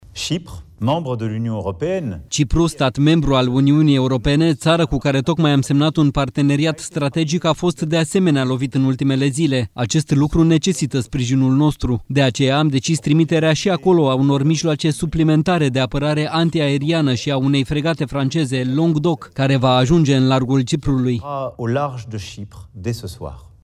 Acesta a declarat, într-un discurs către națiune, că Iran poartă principala responsabilitate pentru războiul din Orientul Mijlociu.
04mar-13-Macron-ce-trimitem-in-cipru-tradus.mp3